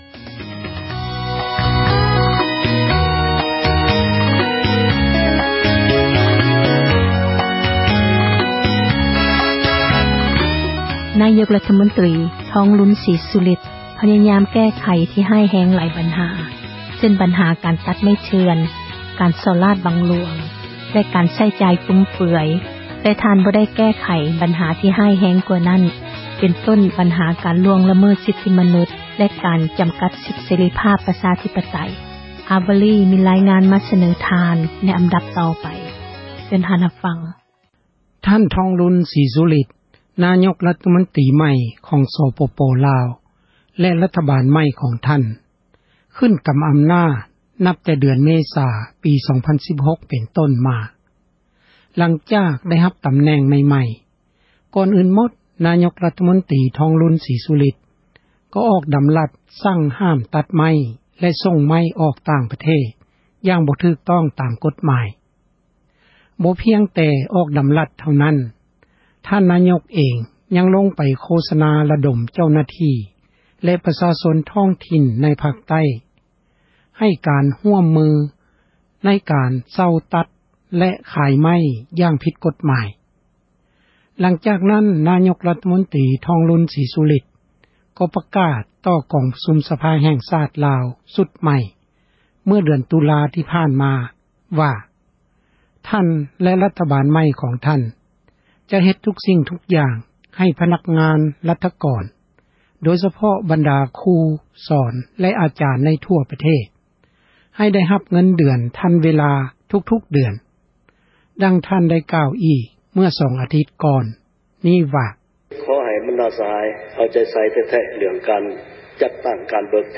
ມີຣາຍງານ ມາສເນີທ່ານ ເຊີນຮັບຟັງ...